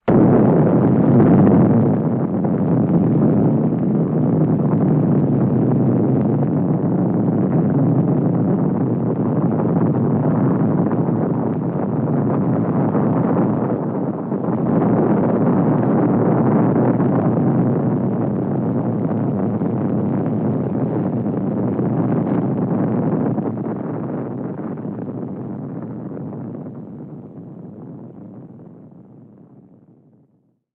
На этой странице представлены записи звуков атомного взрыва — мощные, устрашающие и заставляющие задуматься о хрупкости мира.
Грохот атомного взрыва